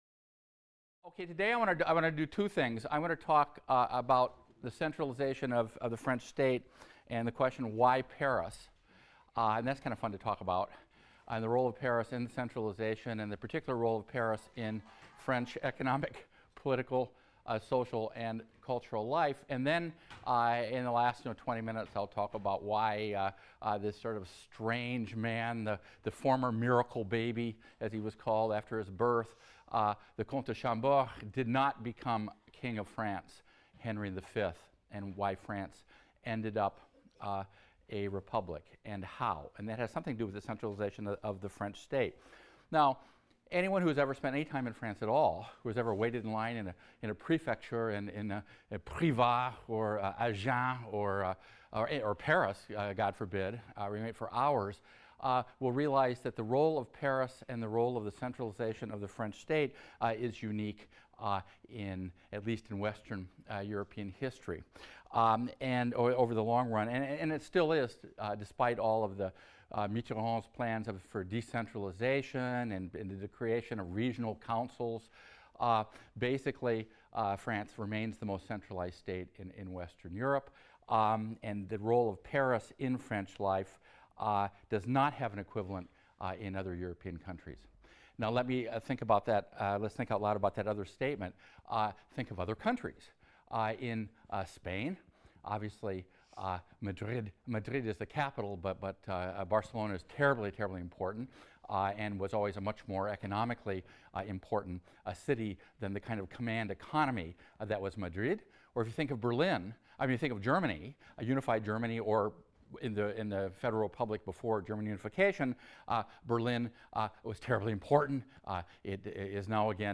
HIST 276 - Lecture 3 - Centralized State and Republic | Open Yale Courses